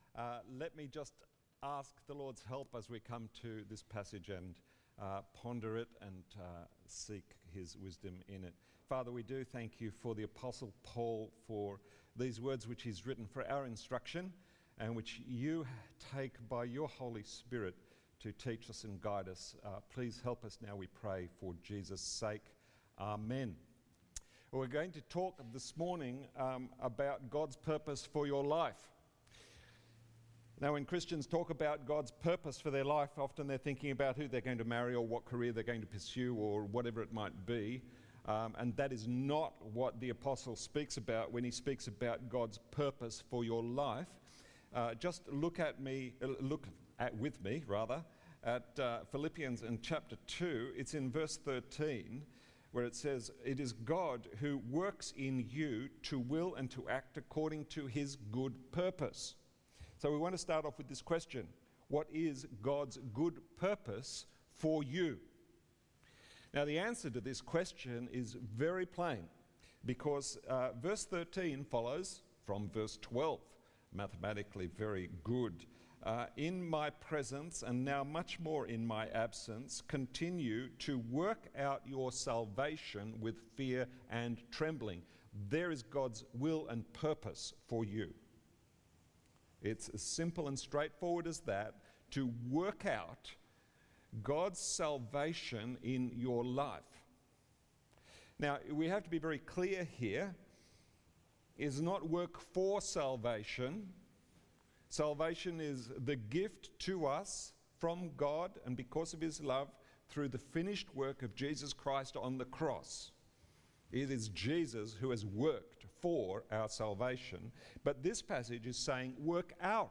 Sunday sermon
from St John’s Anglican Cathedral Parramatta.